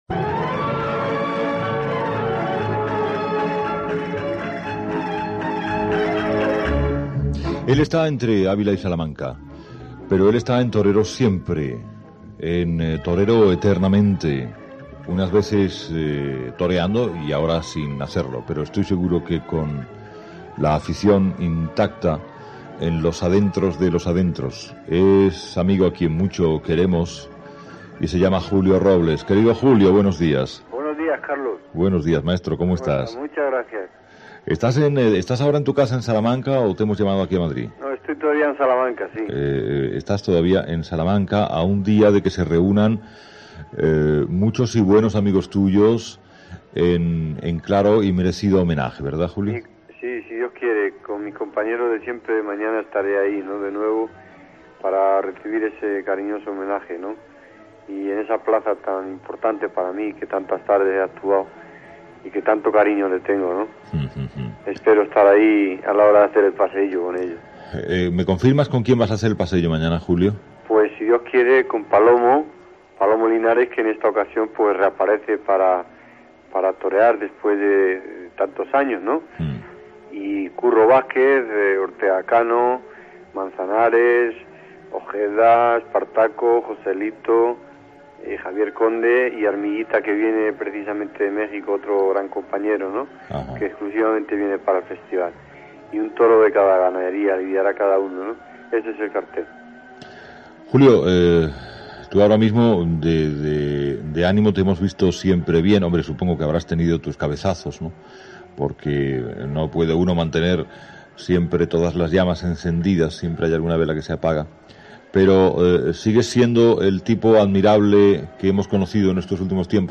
Recordamos la última entrevista de Carlos Herrera a Julio Robles